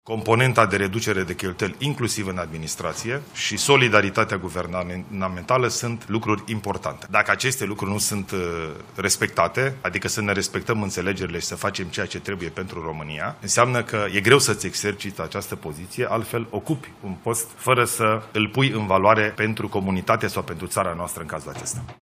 Solidaritatea guvernamentală, un motiv pentru păstrarea portofoliului actualului Guvern, a spus azi Ilie Bolojan: